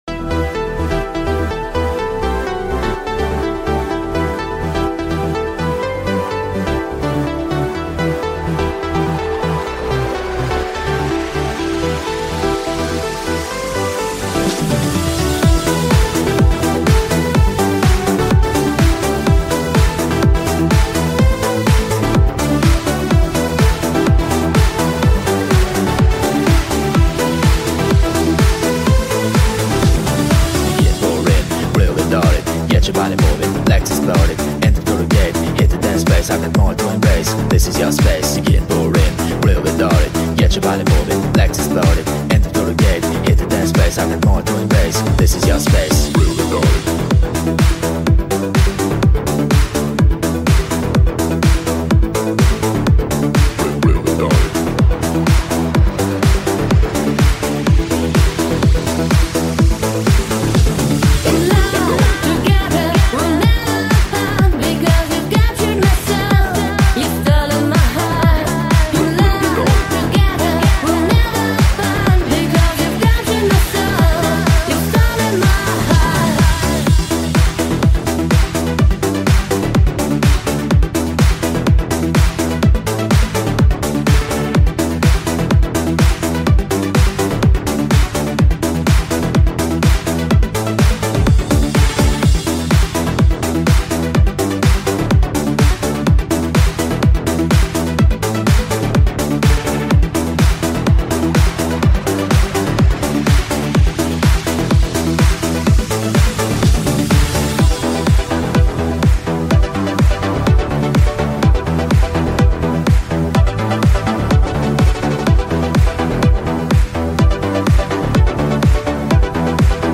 EuroDance-MegaMix.mp3